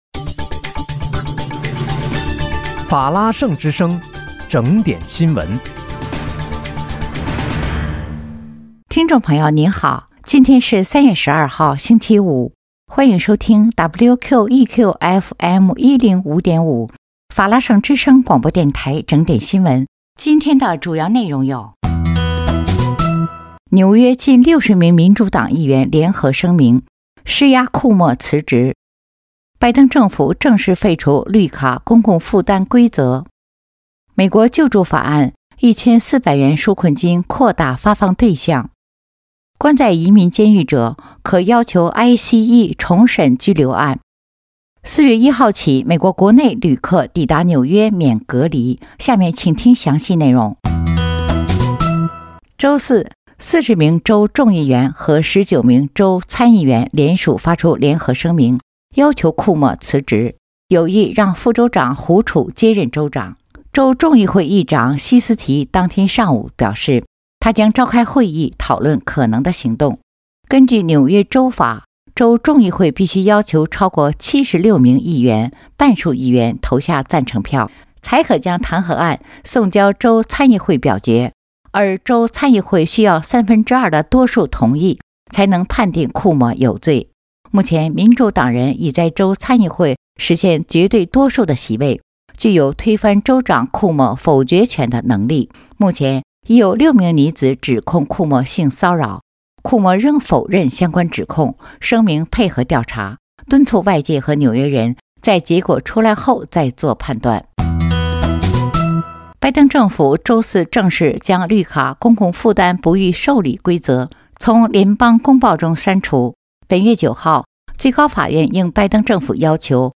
3月12日（星期五）纽约整点新闻